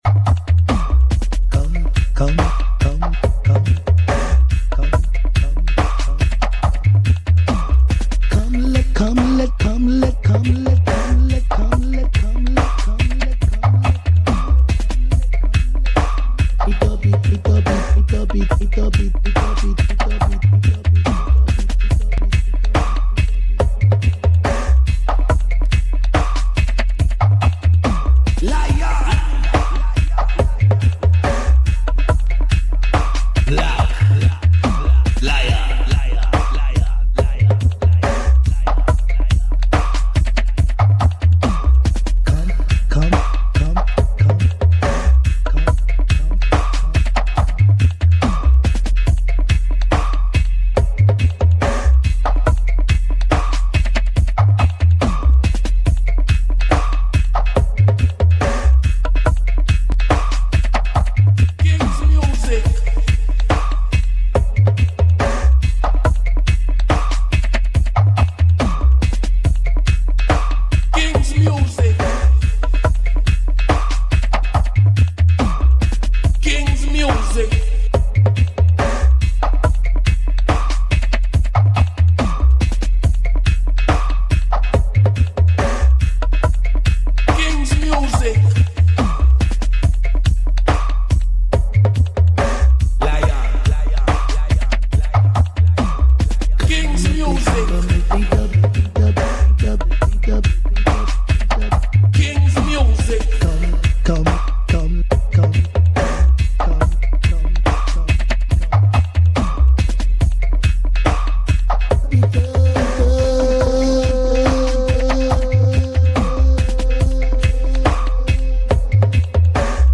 Stream broke 1:31:10 about 8 mins missed !!